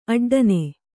♪ aḍḍane